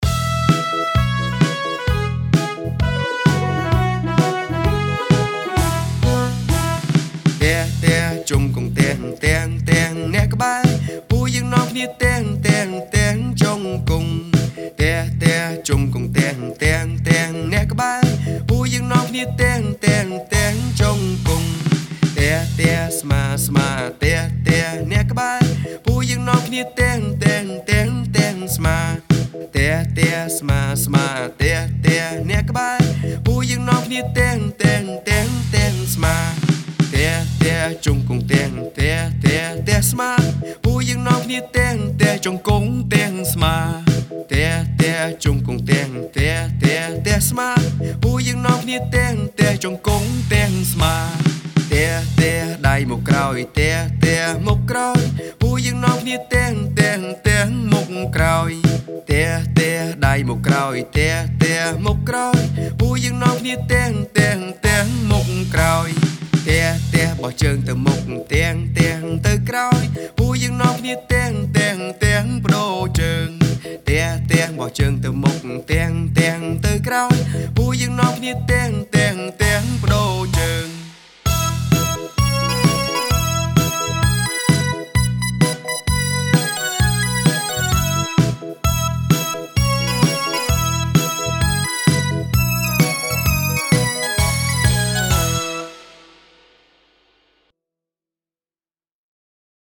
បទចម្រៀង តោះរាំជារង្វង់